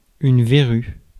Prononciation
Prononciation France: IPA: [yn ve.ʁy] Accent inconnu: IPA: /ve.ʁy/ IPA: /vɛ.ʁy/ Le mot recherché trouvé avec ces langues de source: français Traduction 1.